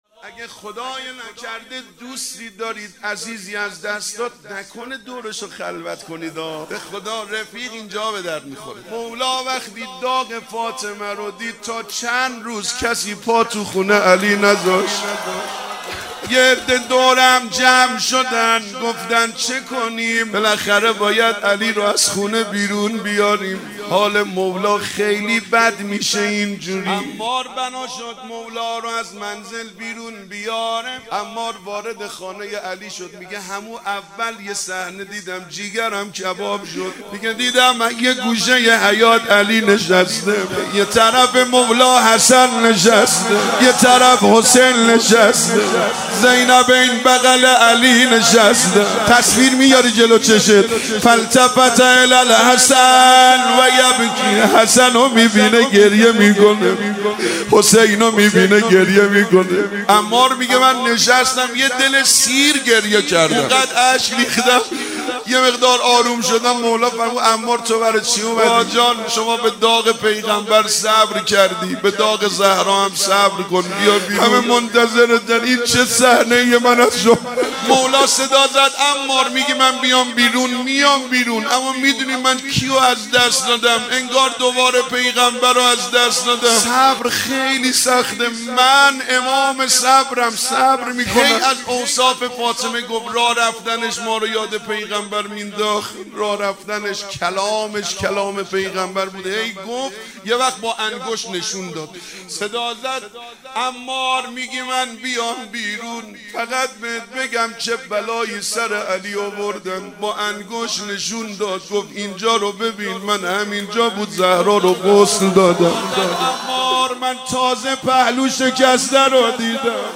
ایام فاطمیه 1441 | محفل عزاداران حضرت زهرا (س) شاهرود